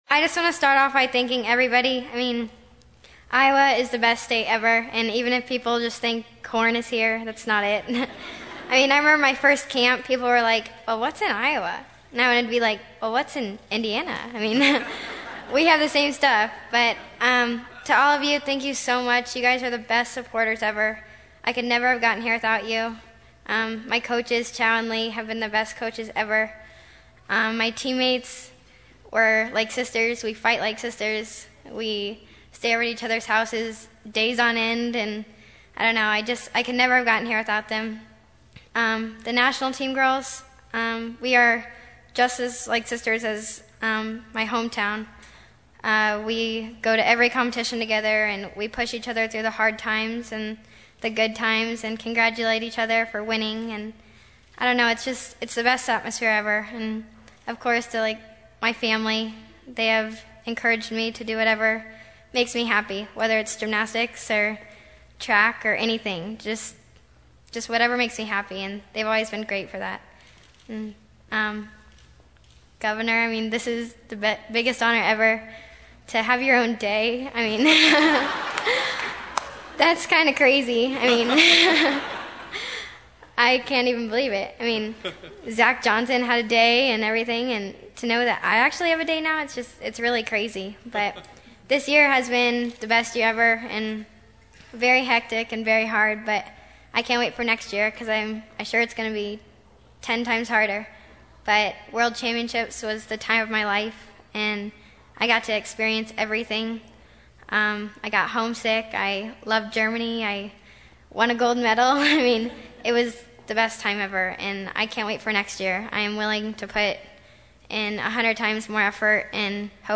"This is the biggest honor ever…to have you’re own day? That’s kinda crazy," Johnson said before a crowd gathered at the State Historical Building.
Audio: Shawn Johnson’s remarks 2:08 MP3